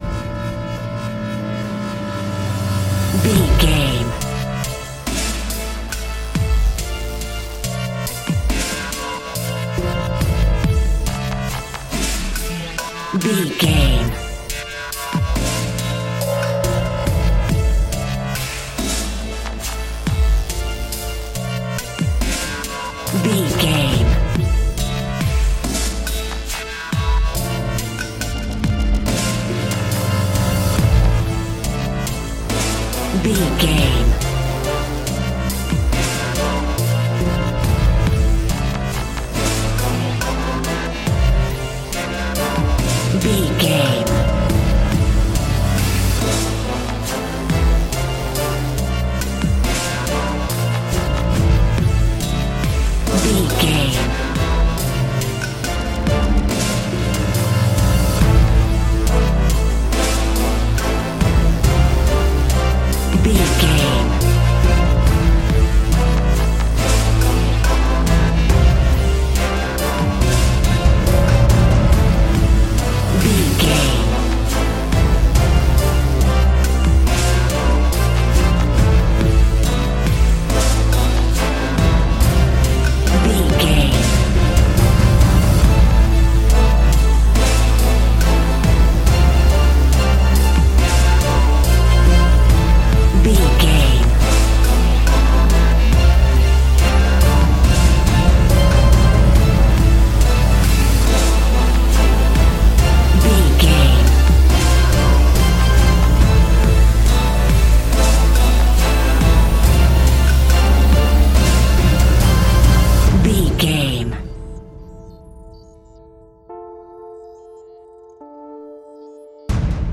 Epic / Action
Aeolian/Minor
strings
brass
drum machine
percussion
driving drum beat